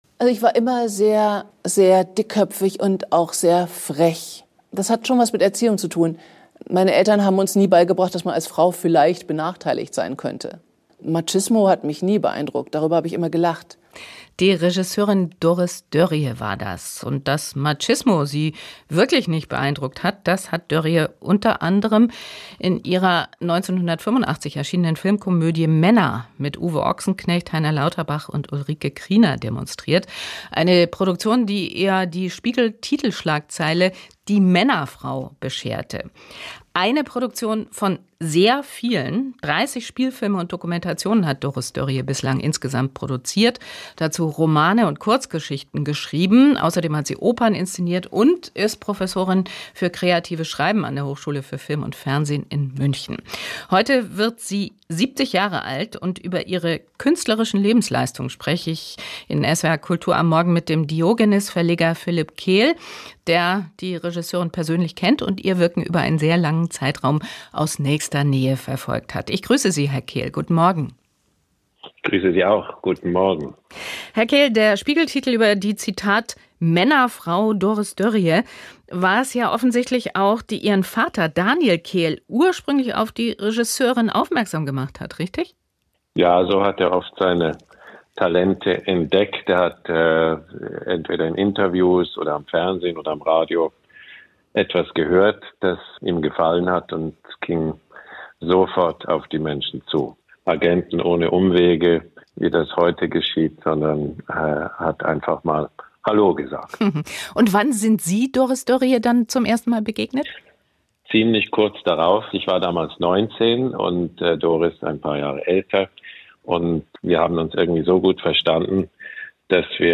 Von Anfang an wollte sie hinaus in die Welt und davon erzählen. Doris Dörrie, Filmemacherin, Autorin, Chronistin weiblicher Innenräume, wird 70 Jahre alt. Ein Porträt.